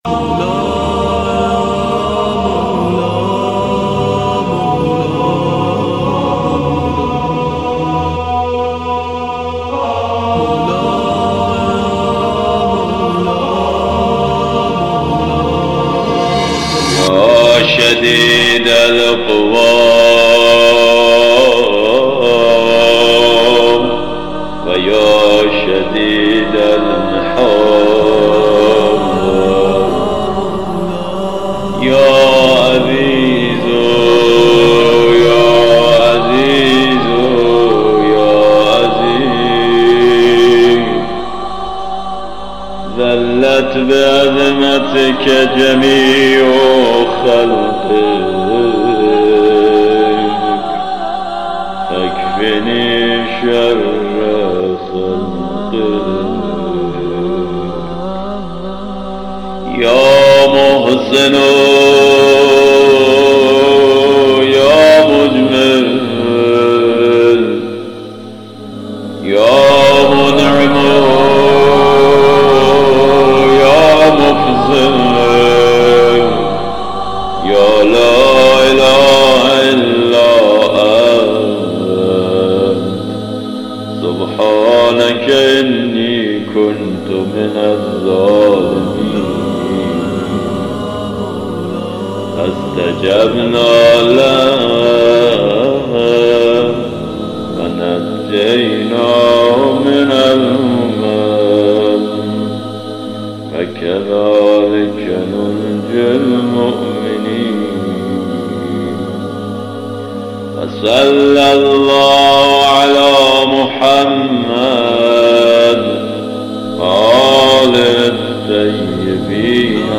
دعای ماه صفر با صدای علی فانی